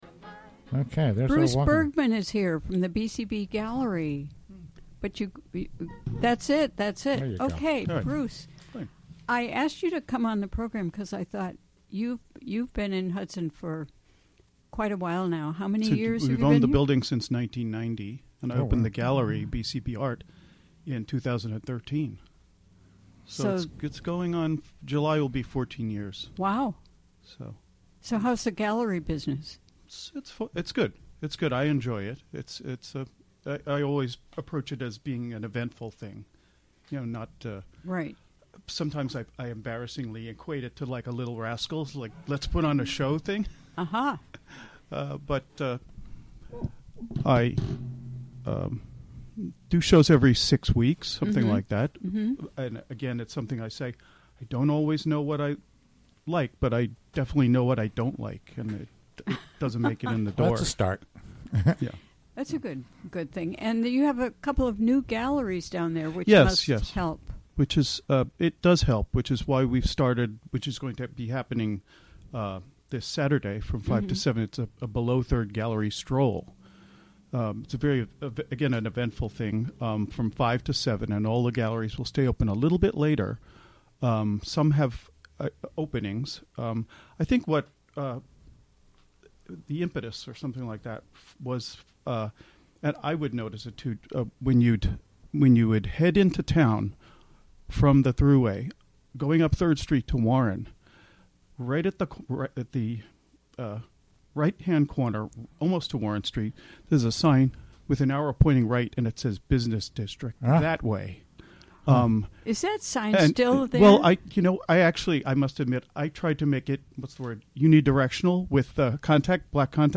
Recorded during the WGXC Afternoon Show Thursday, November 17, 2016.